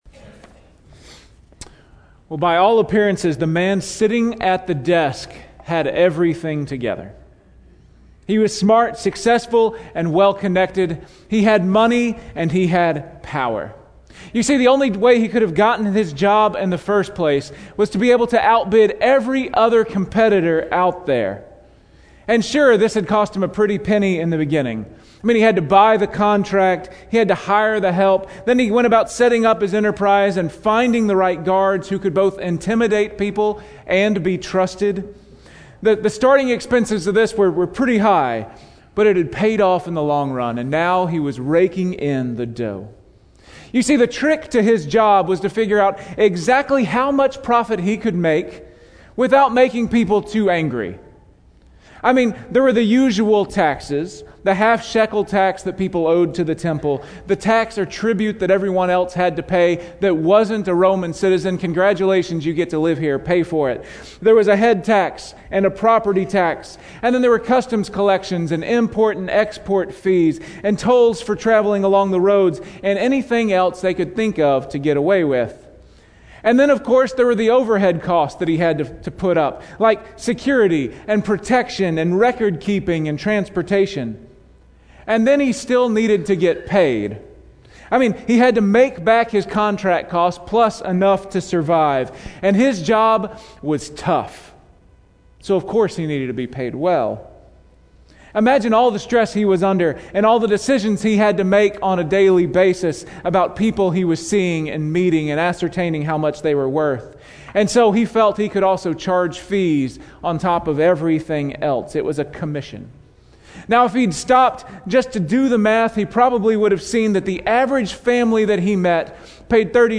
Weekly Sermon Audio